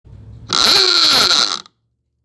Klaxon-3 Horn, 1920s
Great sound, includes the bracket.  Actual horn sound recorded to phone below.